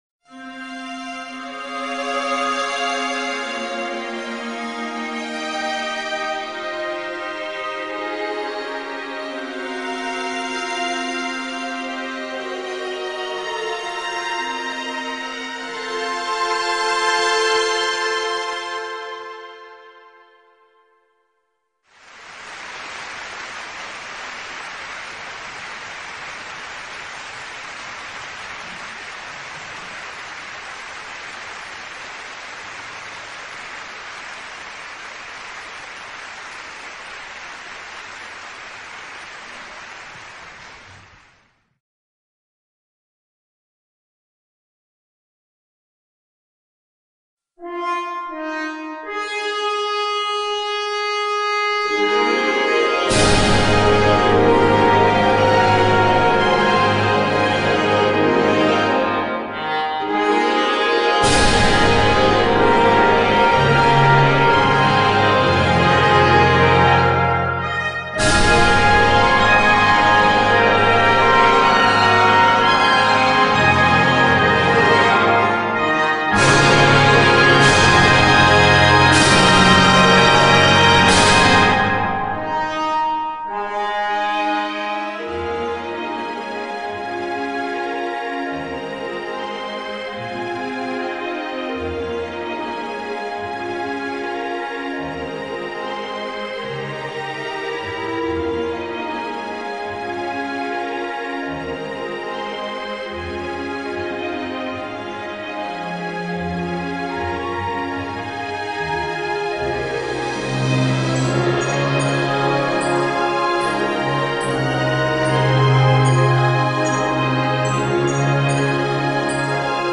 No a já ji našla v orchestrální podobě XD!